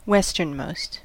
Ääntäminen
Ääntäminen US : IPA : [ˈwɛs.tɚn.ˌmoʊst] Tuntematon aksentti: IPA : /ˈwɛs.tən.ˌməʊst/ Haettu sana löytyi näillä lähdekielillä: englanti Käännöksiä ei löytynyt valitulle kohdekielelle.